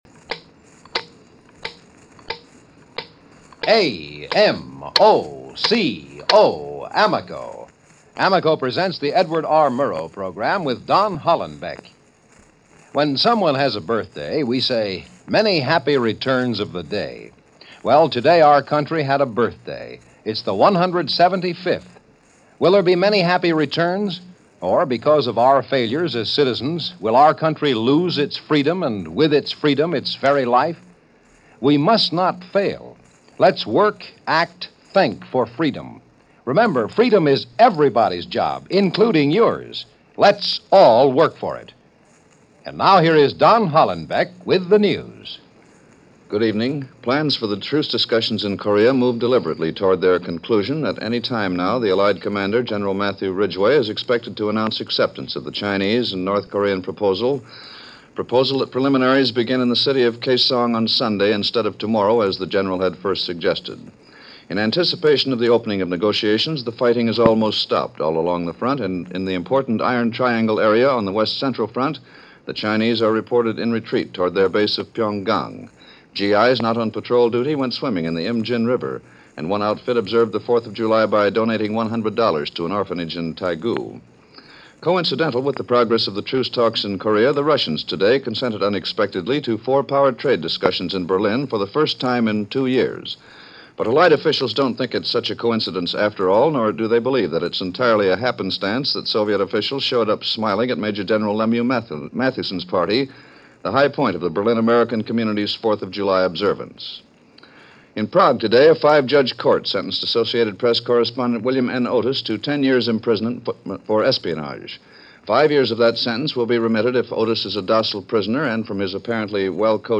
News for this 4th of July in 1951.